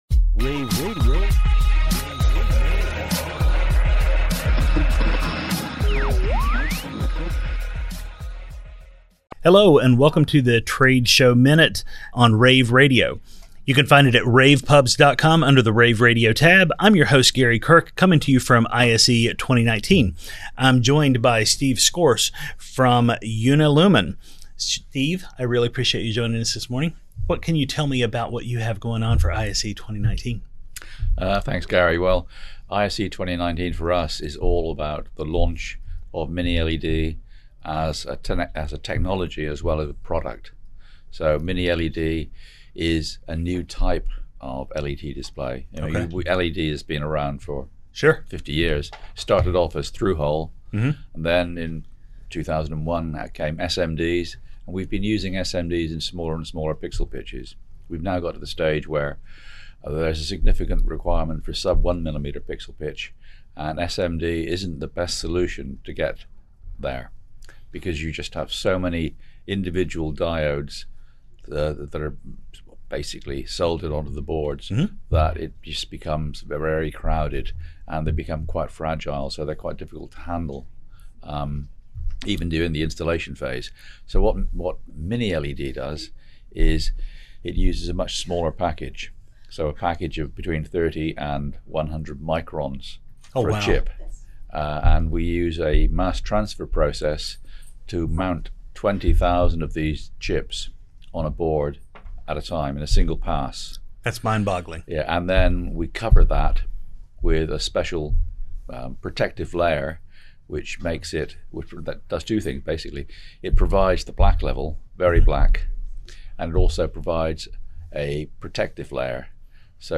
February 6, 2019 - ISE, ISE Radio, Radio, rAVe [PUBS], The Trade Show Minute,